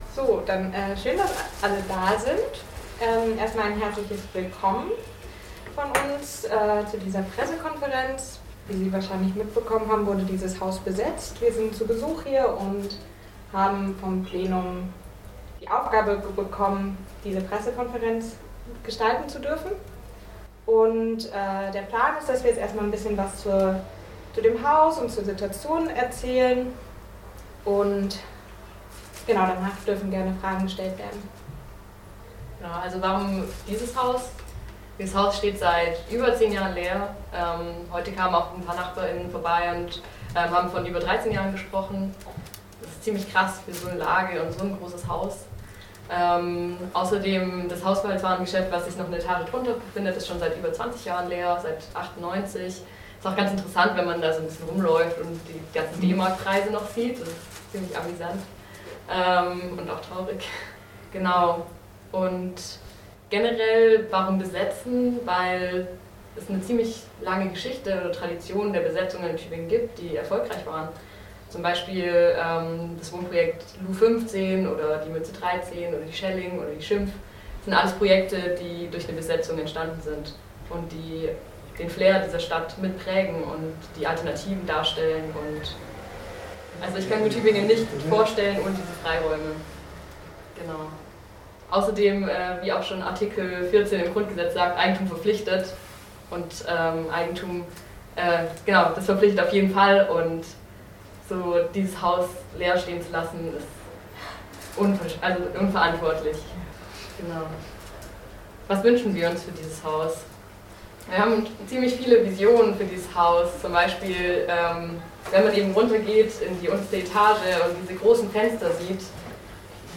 Mitschnitt der Pressekonferenz vom 19. Juli...